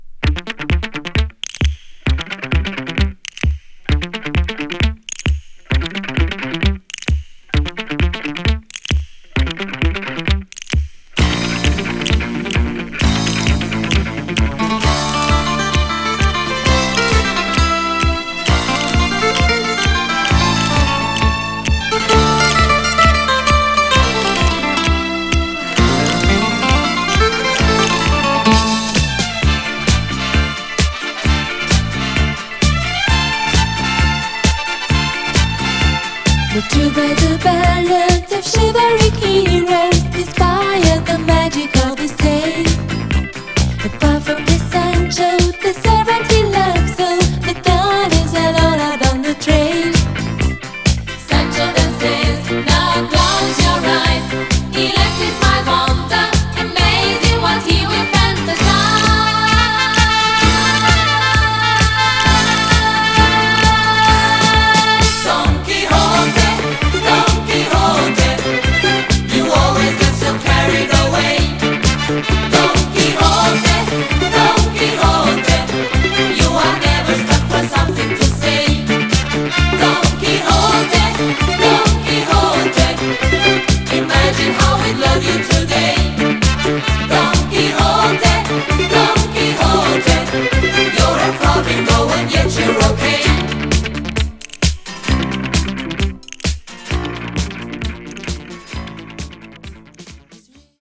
ハンガリーのポップバンド